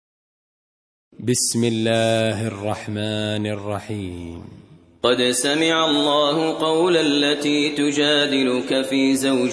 58. Surah Al-Muj�dilah سورة المجادلة Audio Quran Tarteel Recitation
Surah Repeating تكرار السورة Download Surah حمّل السورة Reciting Murattalah Audio for 58.